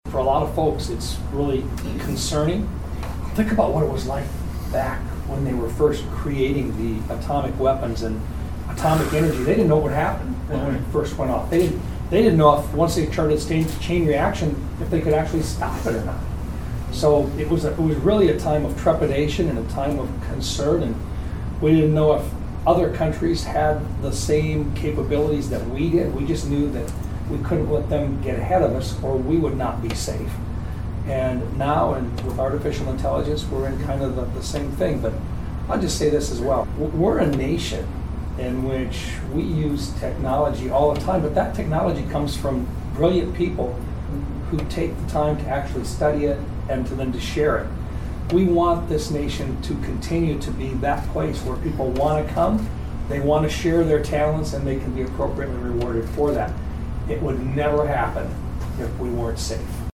At the time, there was a lot we didn’t know or understand about atomic bombs and Senator Rounds says we’re now in a similar situation with artificial intelligence technology.